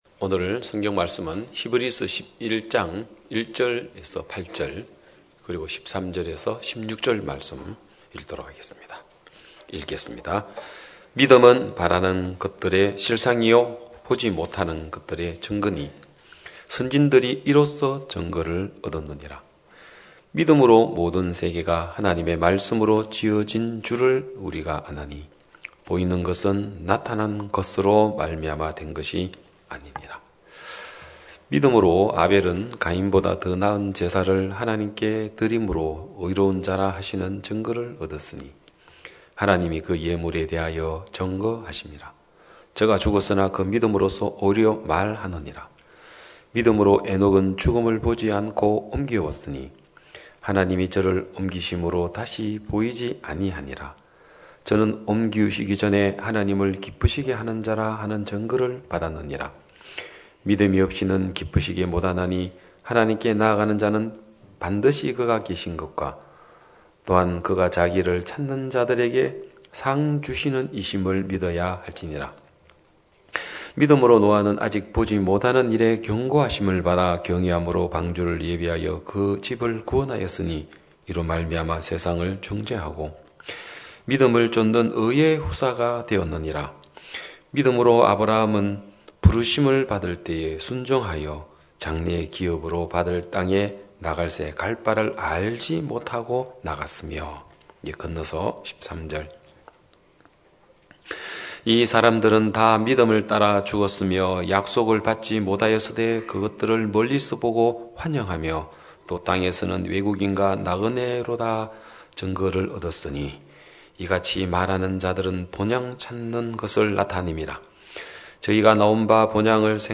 2021년 12월 5일 기쁜소식양천교회 주일오전예배
성도들이 모두 교회에 모여 말씀을 듣는 주일 예배의 설교는, 한 주간 우리 마음을 채웠던 생각을 내려두고 하나님의 말씀으로 가득 채우는 시간입니다.